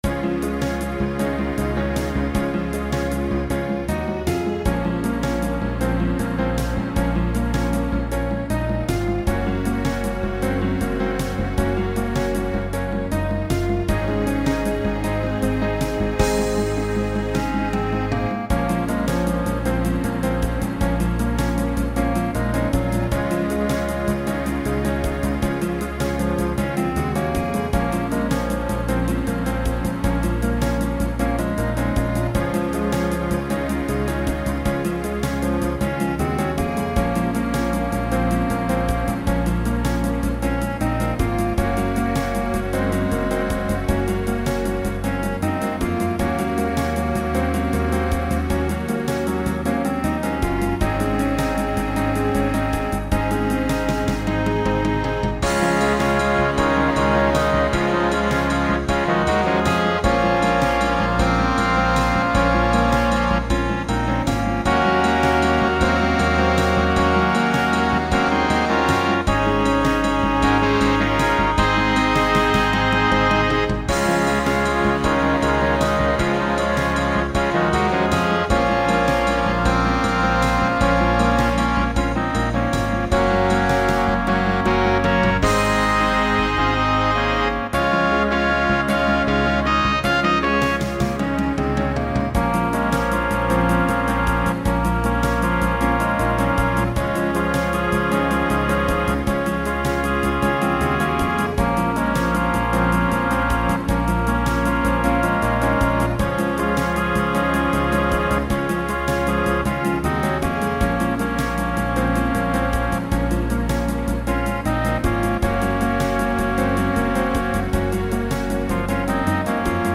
Voicing SATB Instrumental combo Genre Pop/Dance
Show Function Ballad